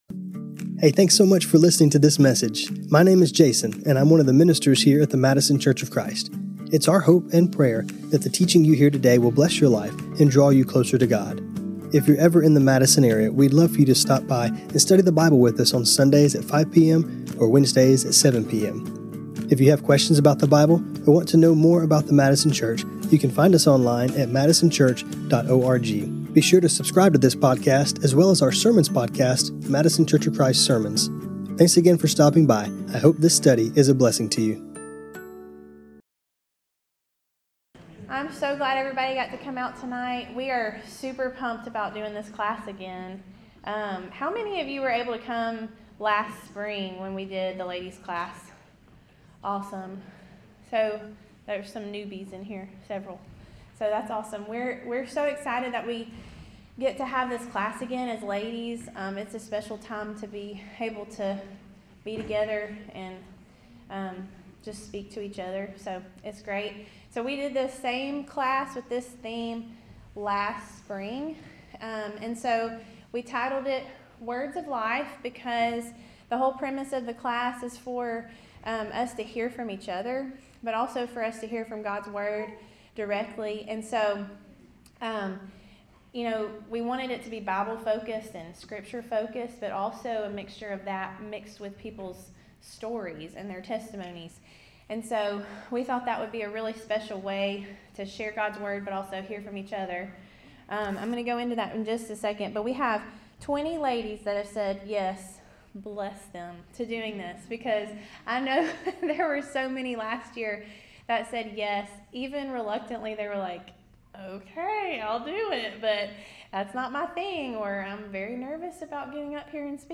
When we go through difficult times and even wonderful times, God has given us something that is constant, His word. In this class, we will hear from some of our own sisters as they share the scriptures that have carried them through different life circumstances both good and bad.